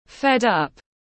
Fed up /ˌfed ˈʌp/
Bạn cũng có thể đọc theo phiên âm của từ fed up /ˌfed ˈʌp/ kết hợp với nghe phát âm sẽ đọc chuẩn hơn.